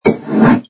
sfx_pick_up_bottle03.wav